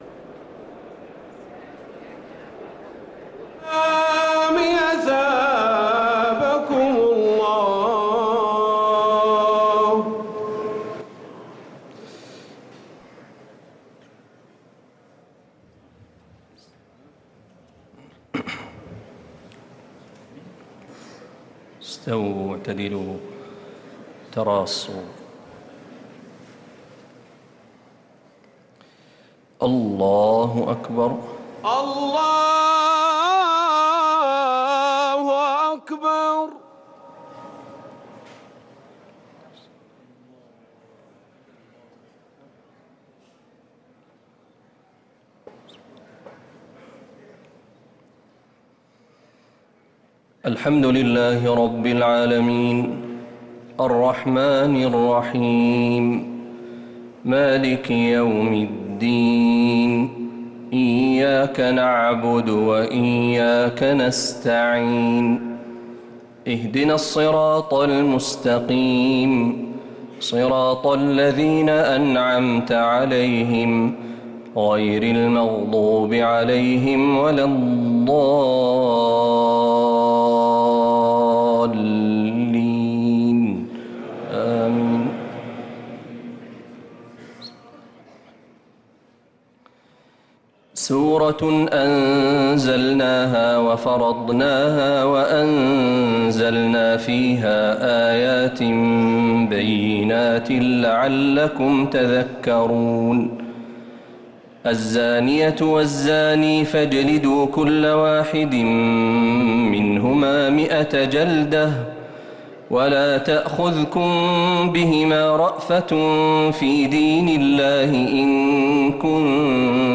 صلاة التراويح ليلة 22 رمضان 1445